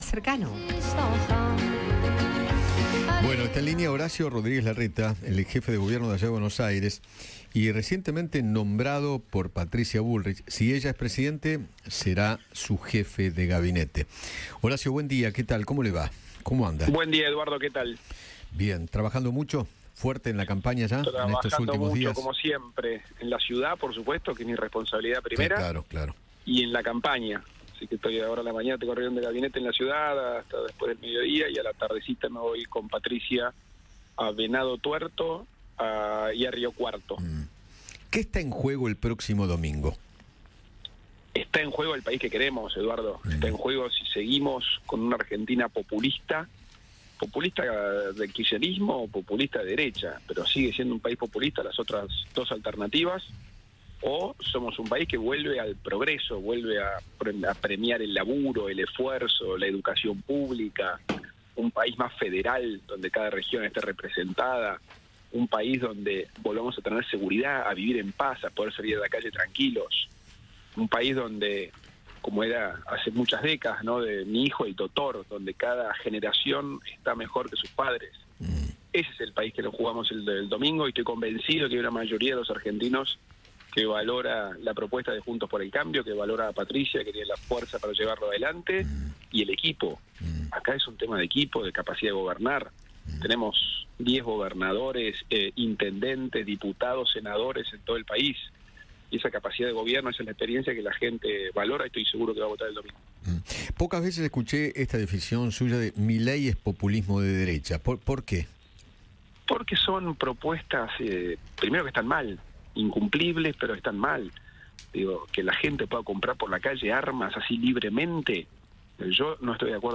Horacio Rodríguez Larreta, jefe de Gobierno porteño, habló con Eduardo Feinmann tras integrarse al equipo de Patricia Bullrich de cara a las elecciones generales 2023.